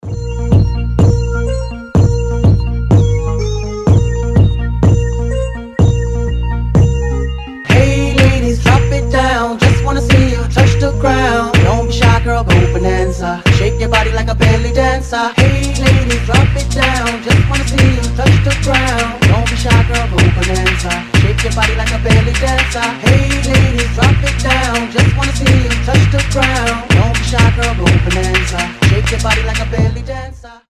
• Качество: 320, Stereo
заводные
dancehall
динамичные
Mashup
ремиксы
Заводной ремикс песенки из 2003 года